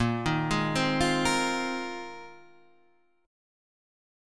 A# 7th Suspended 2nd Suspended 4th